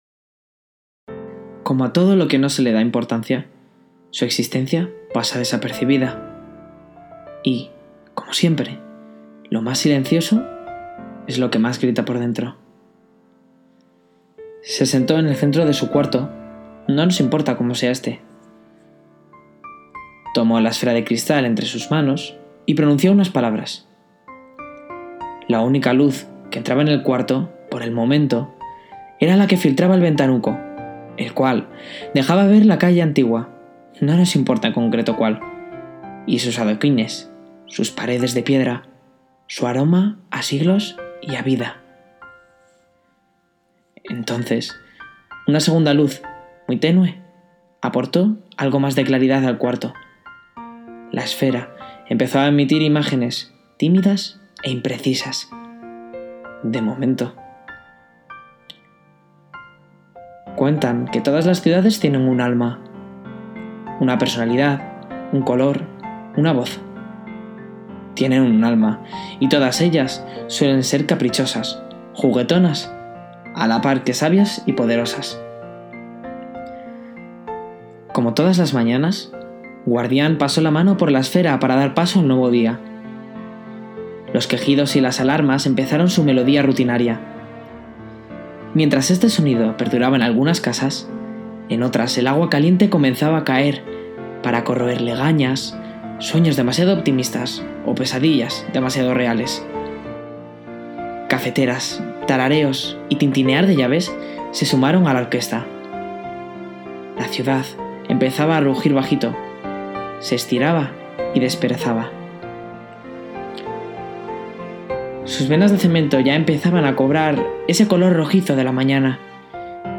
(Versión narrada al final*) Como todo a lo que no se le da importancia, su existencia pasa desapercibida.